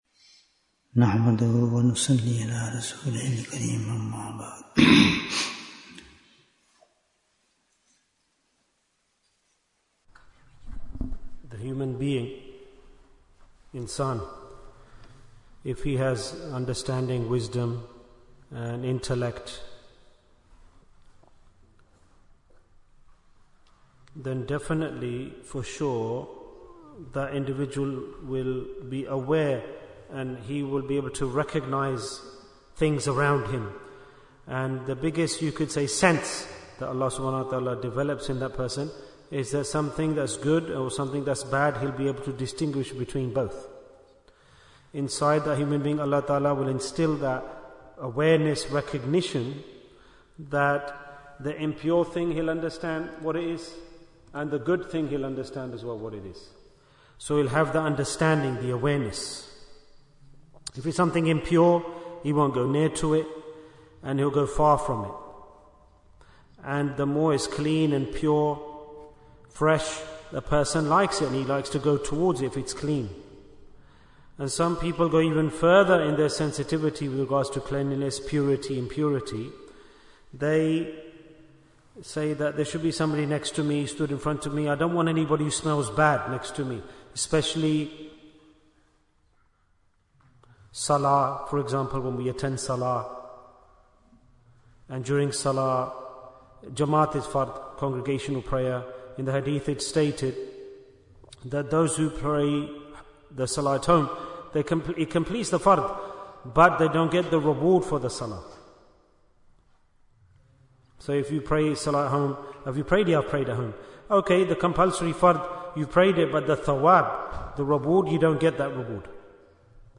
Jewels of Ramadhan 2025 - Episode 2 Bayan, 36 minutes2nd March, 2025